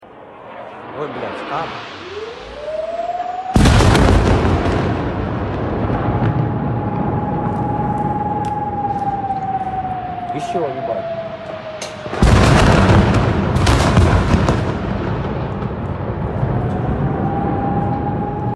drone rudal masihenghujai kota Kharkiv sound effects free download